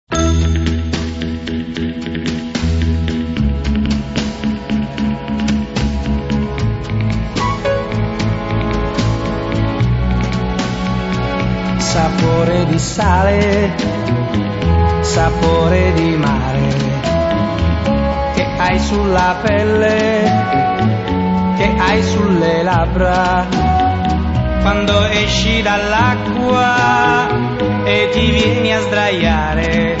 • musica leggera
• Light music